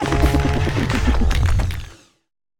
Cri de Vrombotor dans Pokémon Écarlate et Violet.